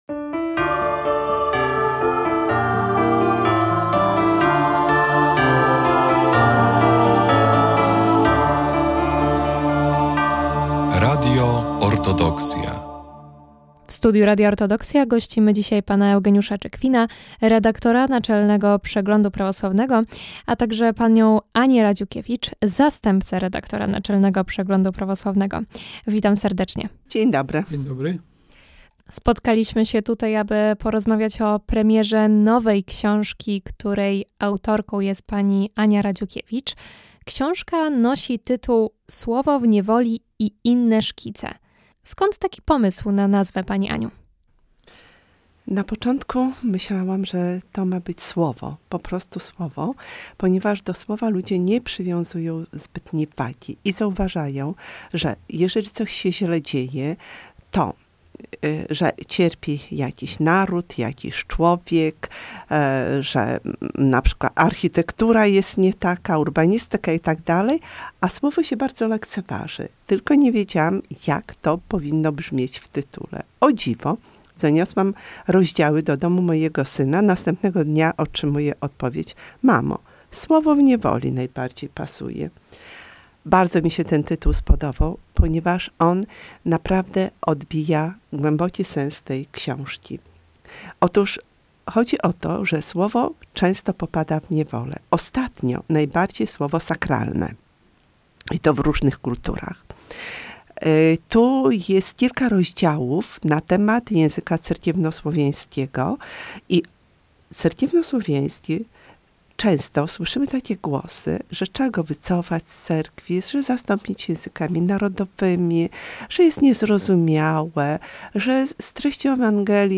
Prowadząca prezentuje i omawia wybrane lektury warte uwagi, często związane z kulturą, historią i duchowością, dzieląc się refleksjami i czytelniczymi rekomendacjami.